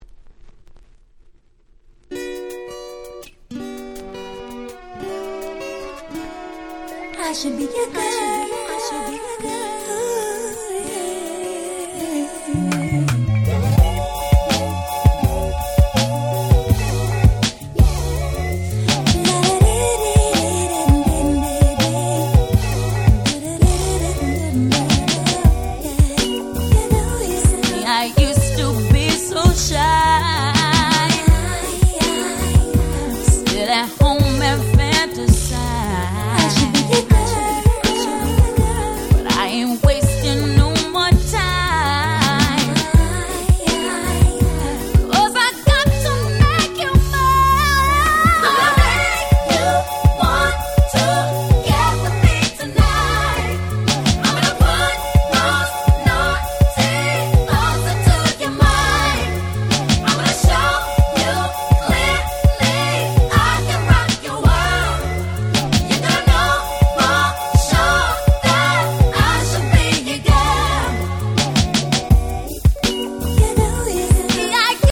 05' White Press Only R&B 12'' !!